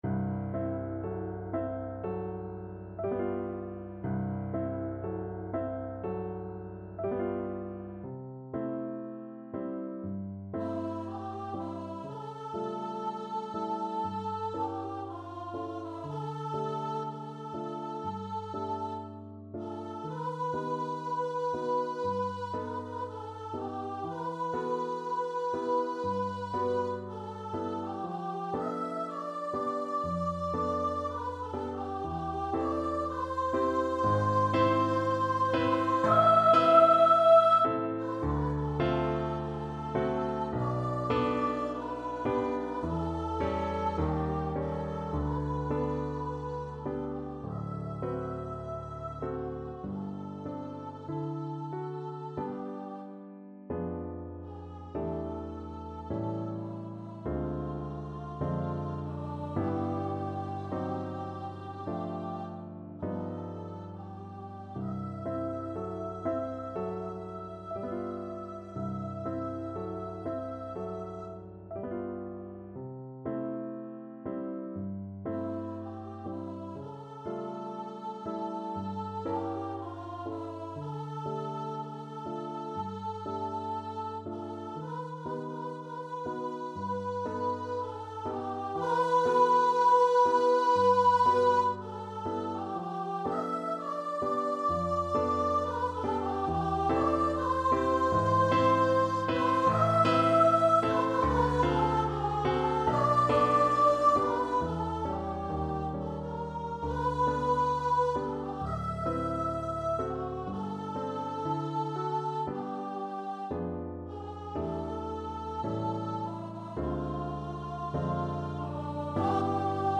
D5-E6
Classical (View more Classical Voice Music)